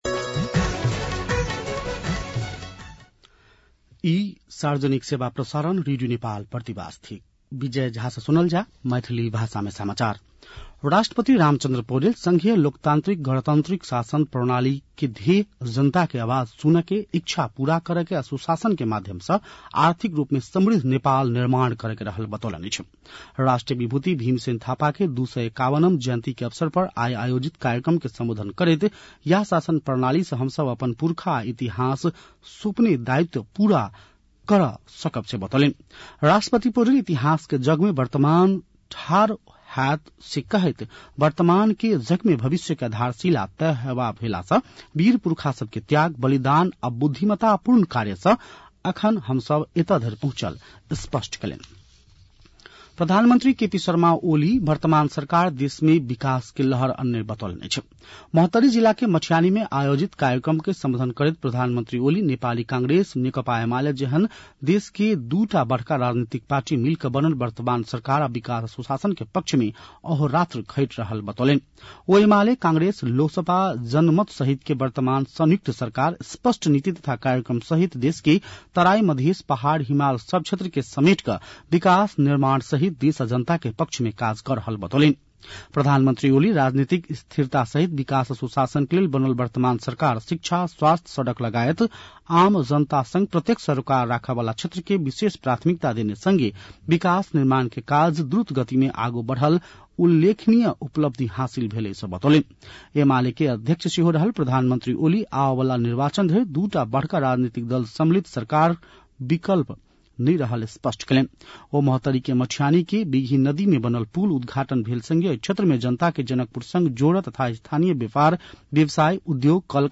मैथिली भाषामा समाचार : ९ साउन , २०८२
Maithali-news-4-09.mp3